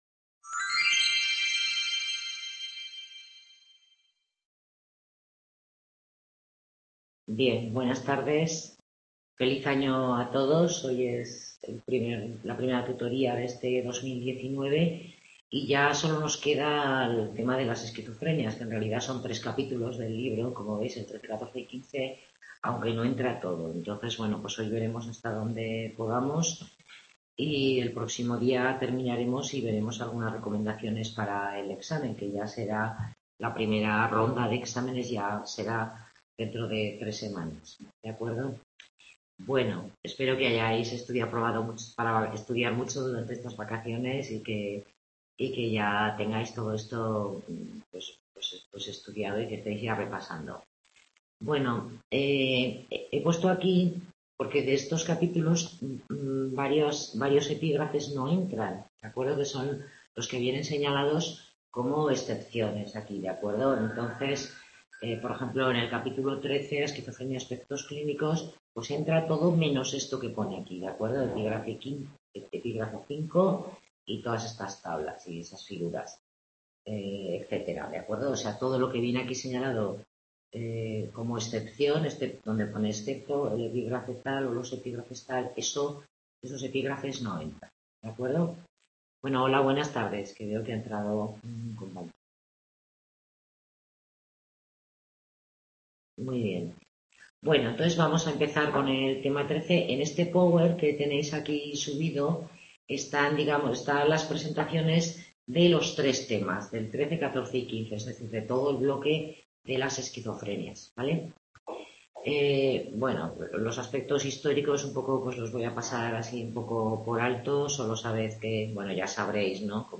Tutoría grupal en la que se revisan aspectos clínicos de la Esquizofrenia